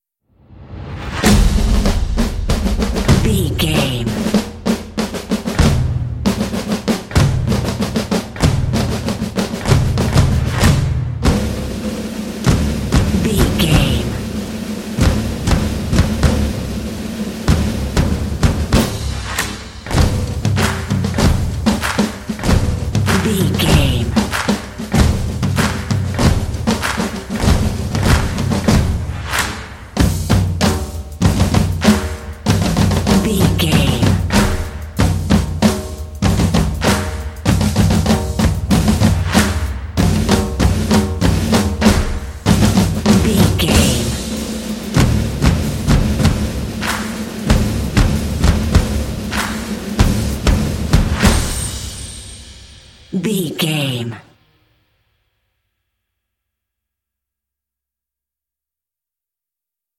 This epic drumline will pump you up for some intense action.
Epic / Action
Atonal
driving
motivational
drums
percussion